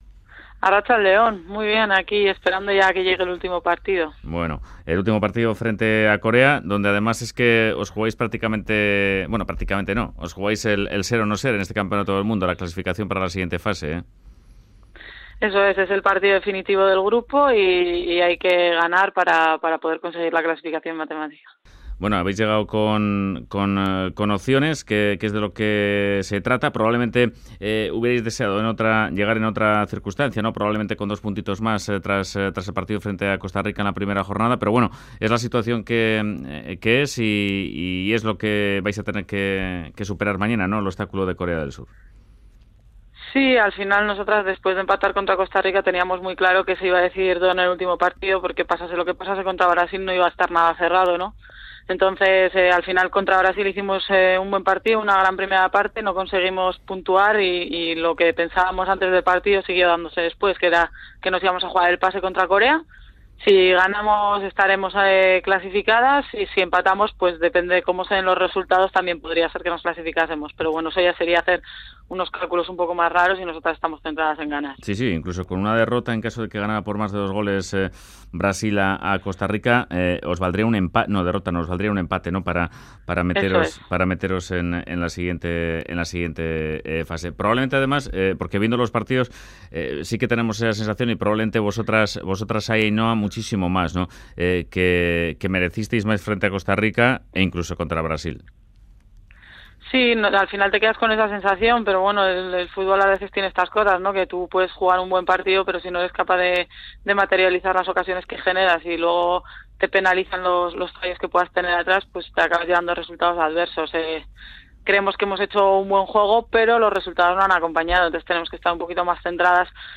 La jugadora del Athletic habla en Fuera de Juego desde la concentración de la selección española en Canadá antes del partido contra Corea del Sur en el Mundial